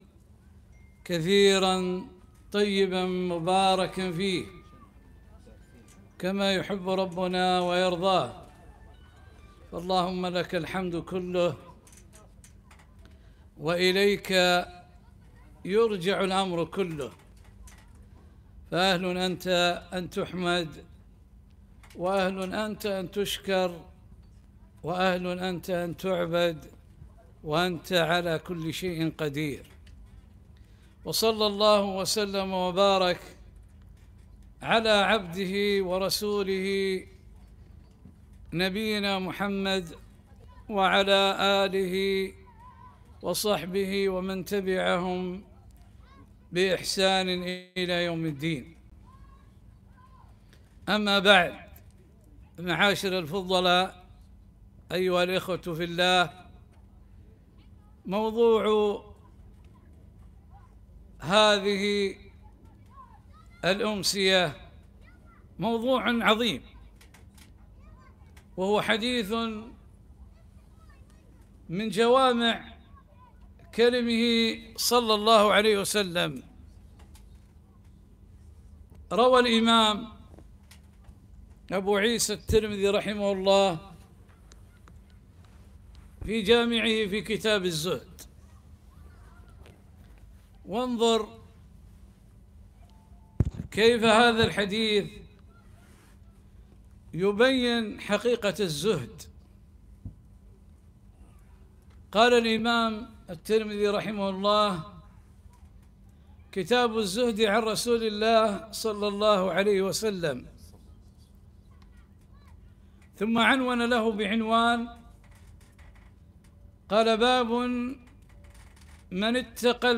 محاضرة - اتق المحارم تكن أعبد الناس